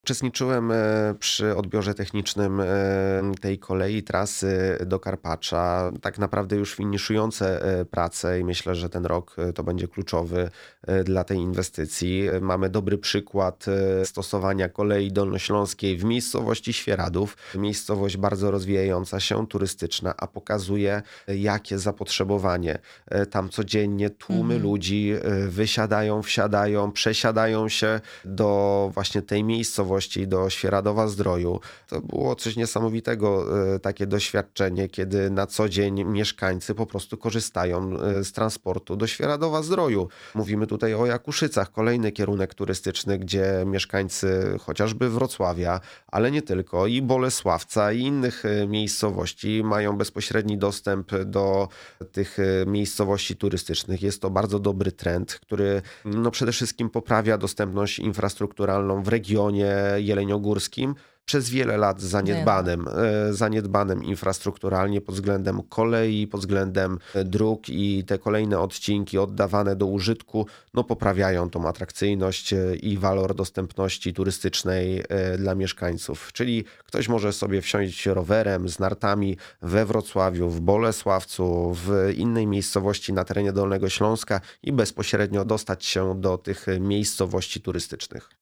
Radny Sejmiku Kamil Barczyk w audycji „Dolny Śląsk z bliska”
Jest nim Kamil Barczyk radny Sejmiku Województwa Dolnośląskiego (Klub Radnych: Trzecia Droga – Polskie Stronnictwo Ludowe).